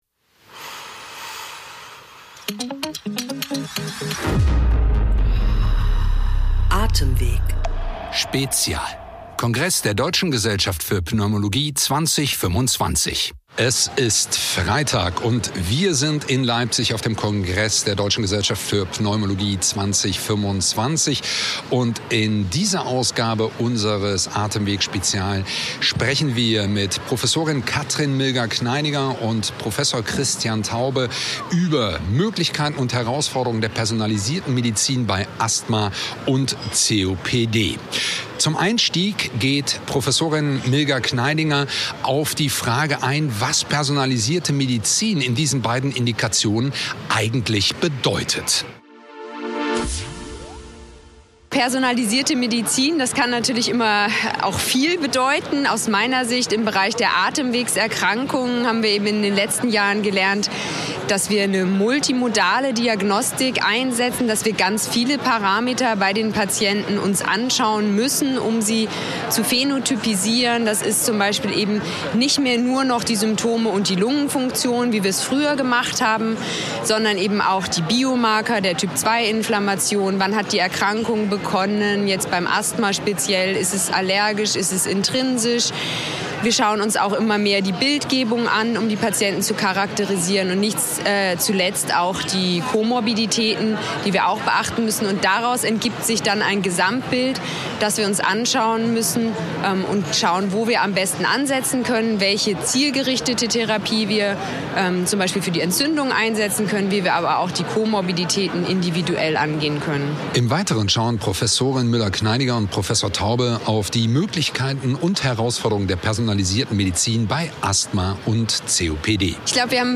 eine besondere Podcastreihe – direkt aus dem Congress Center